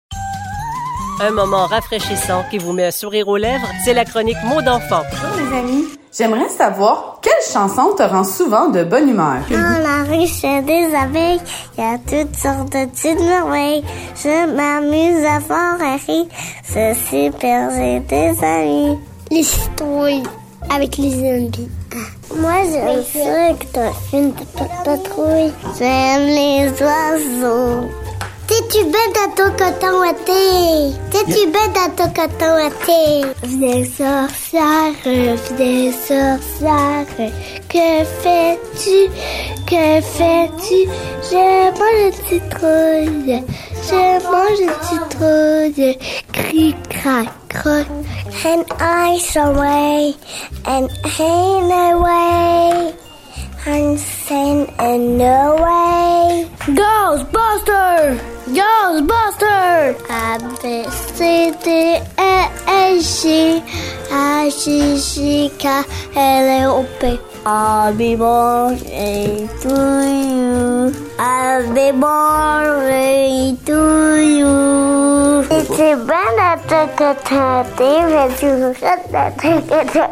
Les enfants du CPE La Bottine nous disent quelle est la chanson qui les rend habituellement de bonne humeur.